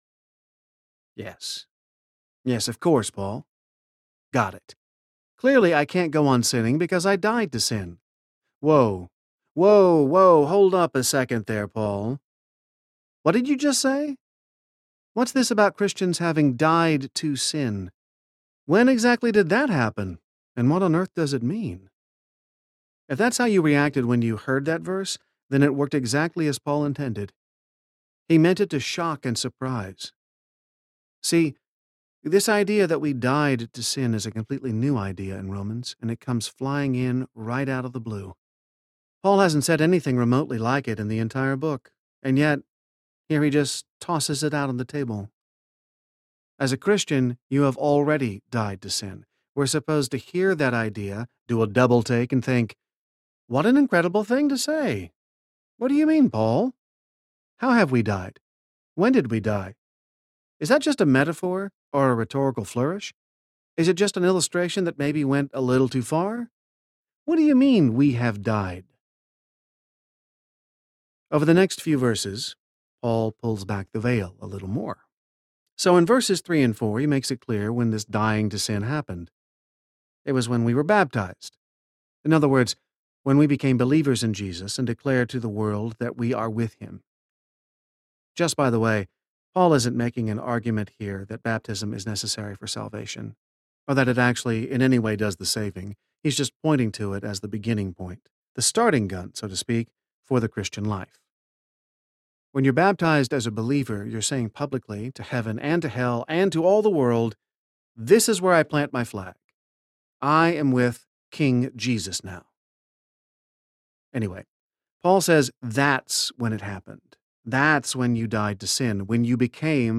Favor Audiobook
4.93 Hrs. – Unabridged